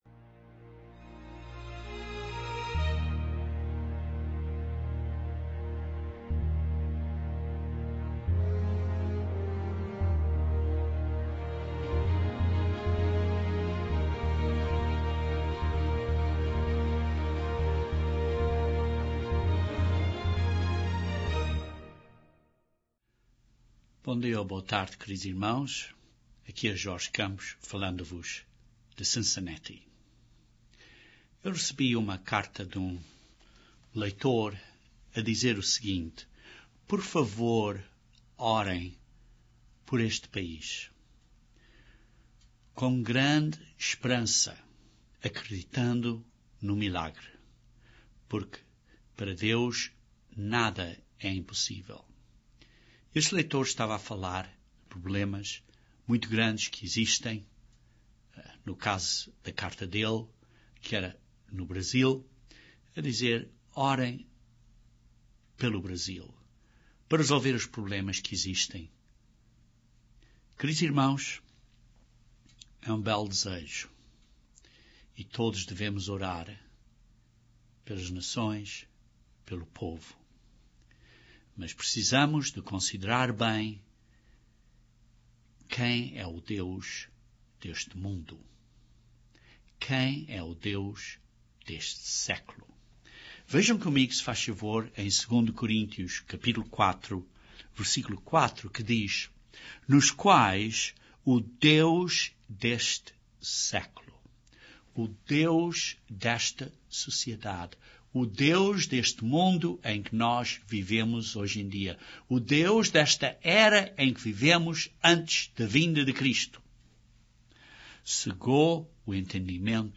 Este sermão descreve algumas estratégicas pelas quais o deus deste mundo nos pode destruir. Você o pode vencer através da autoridade de Jesus Cristo, o poder do Espírito e a Palavra de Deus (a Bíblia).